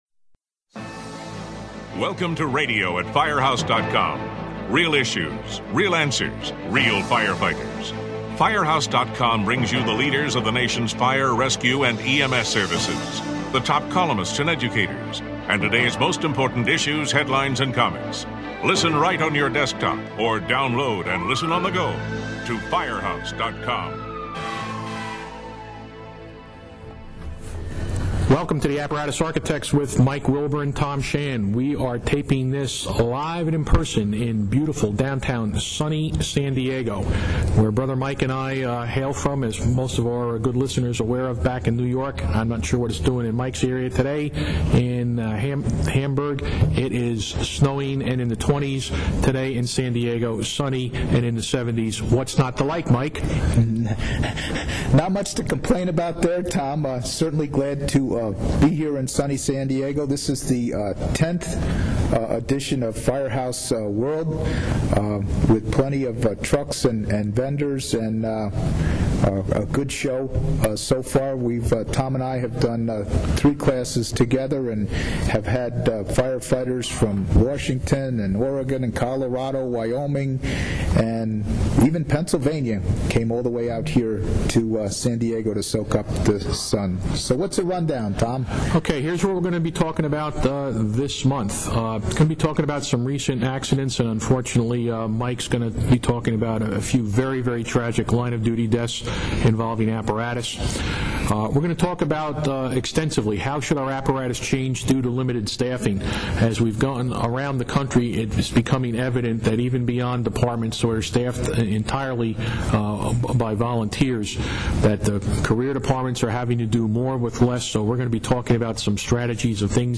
recorded this podcast at Firehouse World